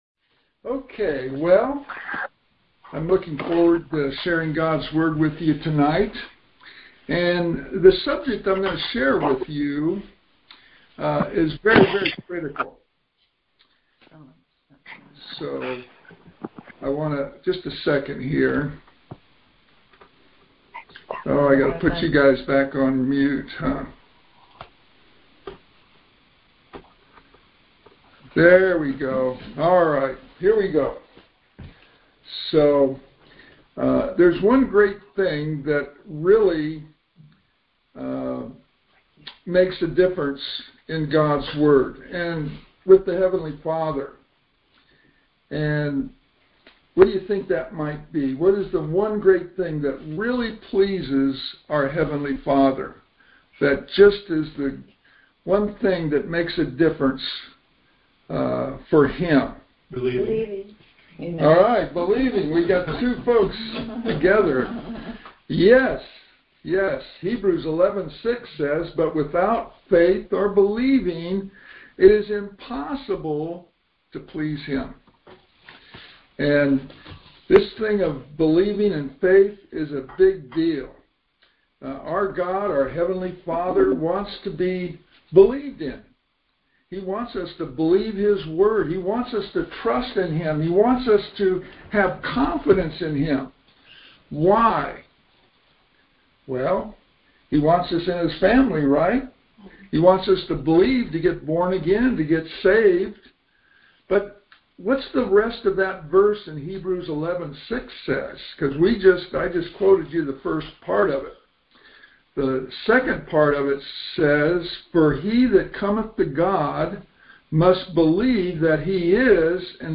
This teaching is not filled with a bunch of keys to believe, it has a simple solution to manifest the promises of God.